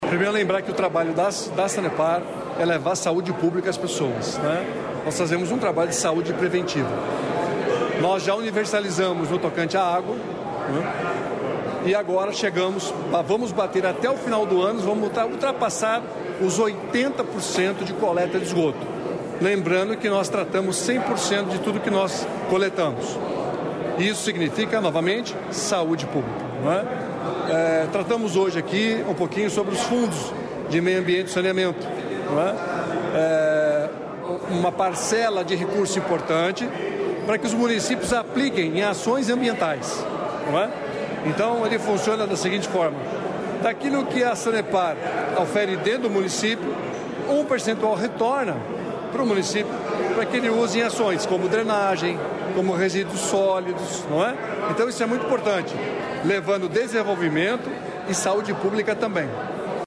Sonora do presidente da Sanepar, Claudio Stabile, sobre contratos com a Sanepar